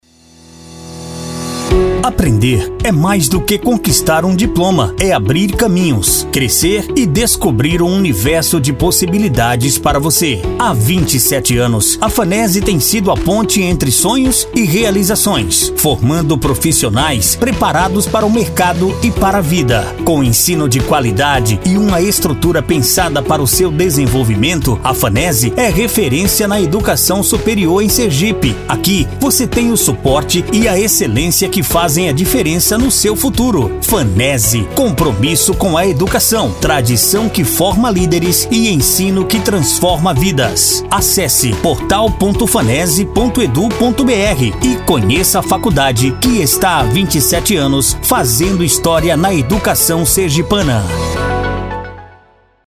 INSTITUCIONAL :